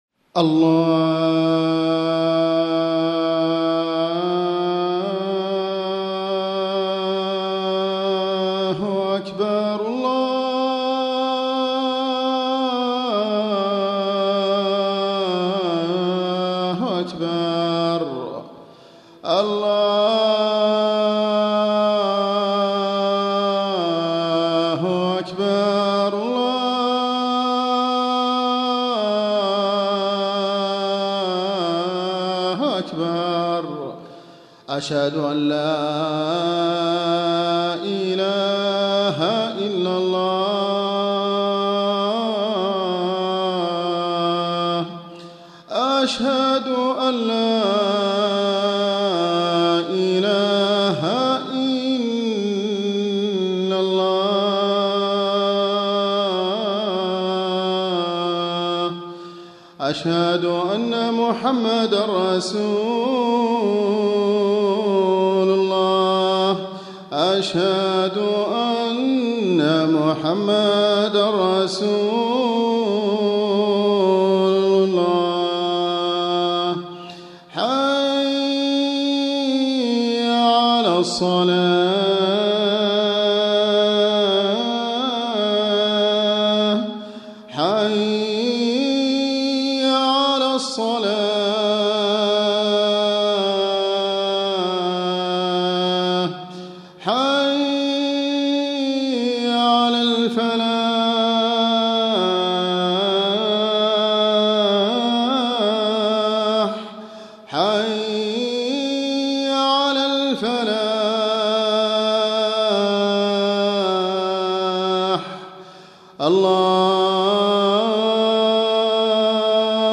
آذان
المكتبة الصوتية روائع الآذان المادة آذان